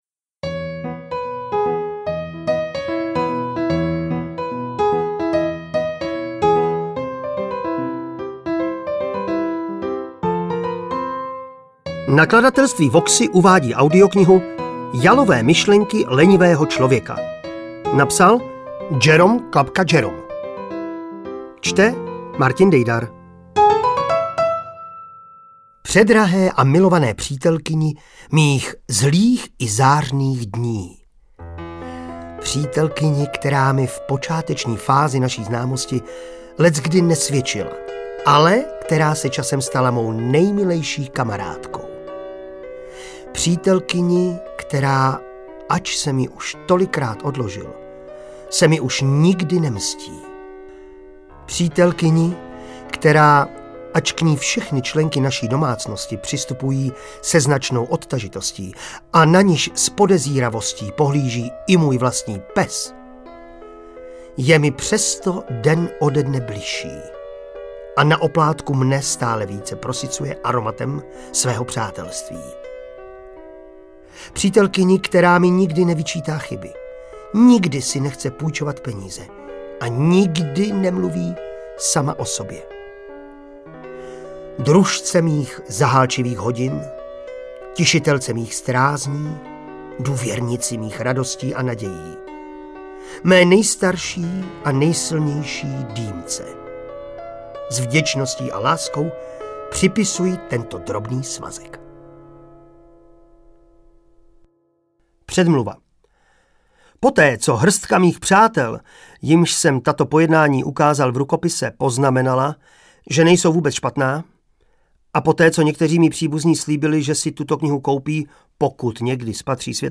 Interpret:  Martin Dejdar
AudioKniha ke stažení, 15 x mp3, délka 5 hod. 10 min., velikost 425,3 MB, česky